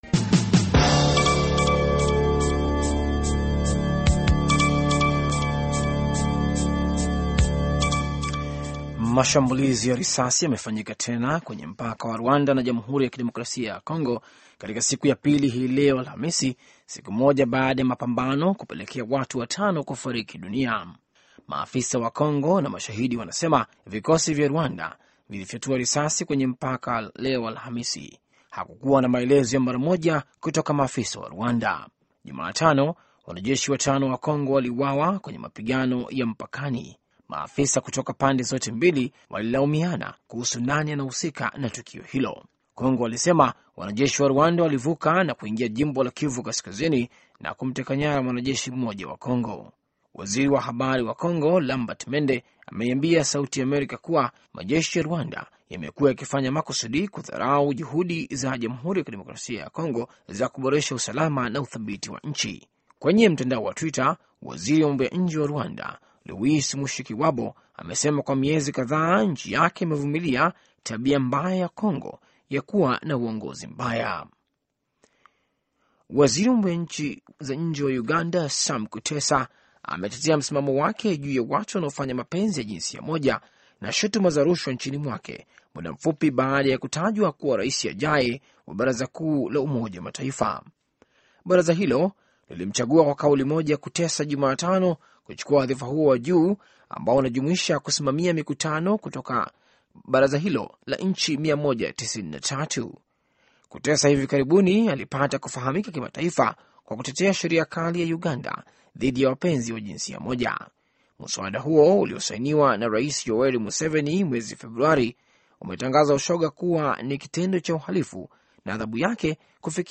Taarifa ya Habari VOA Swahili - 6:25